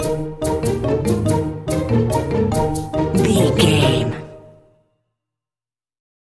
Ionian/Major
E♭
percussion
synthesiser
piano
strings
silly
circus
goofy
comical
cheerful
perky
Light hearted
quirky